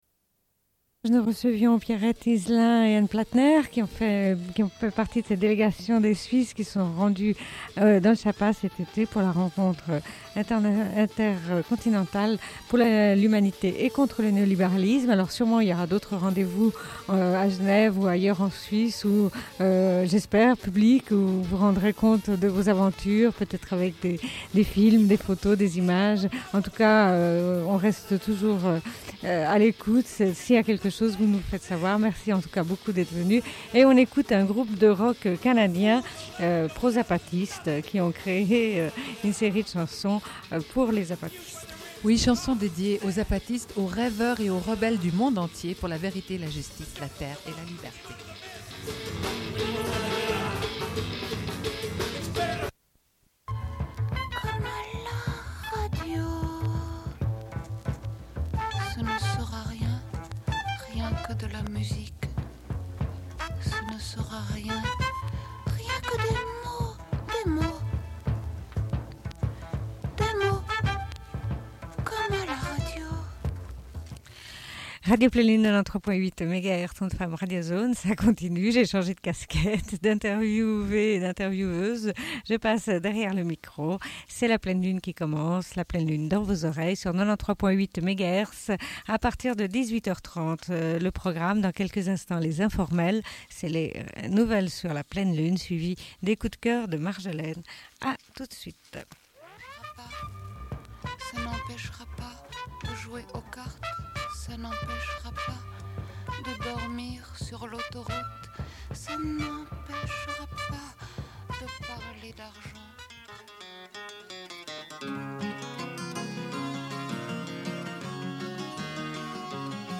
Bulletin d'information de Radio Pleine Lune
Une cassette audio, face B